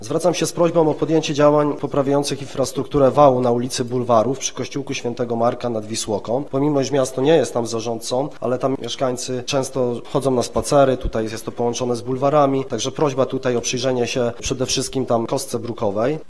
O jej naprawę na ostatniej sesji Rady Miasta wnioskował radny PiS Jakub Blicharczyk (na zdjęciu):